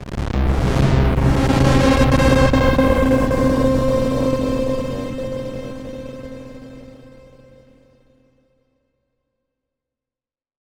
Amb1n2_c_synth_c_distortion2.wav